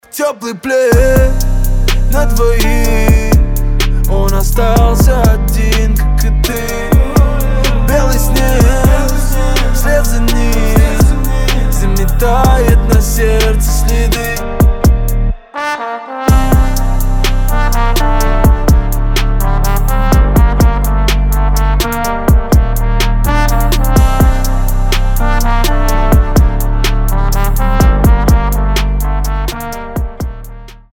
лирика
Саксофон
труба